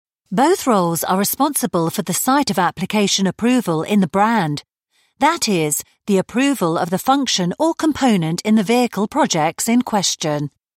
With my clear, bright British tones, I bring a touch of class to every project, ensuring your message resonates with listeners everywhere.
Words that describe my voice are British, Friendly, Natural.
0811Approachable.VW_E_Learning.mp3